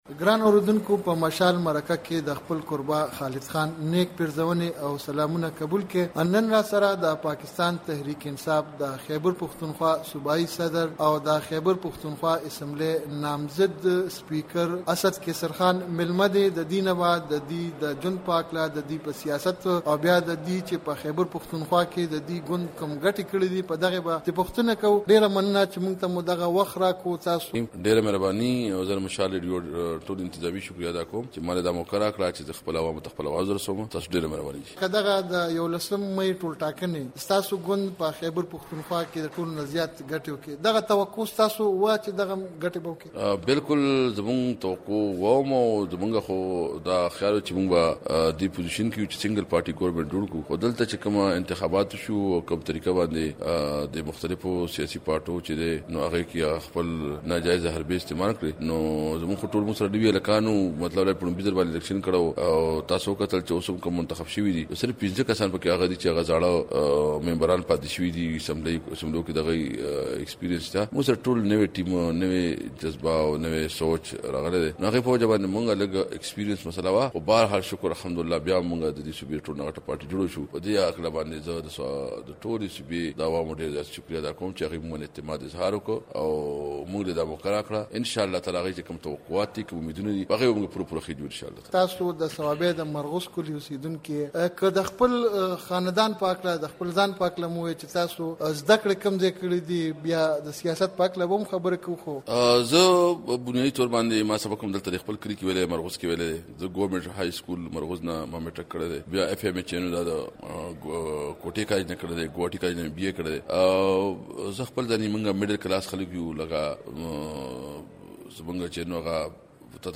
د خیبرپښتونخوا له نامزد سپیکر اسد قیصرخان سره مرکه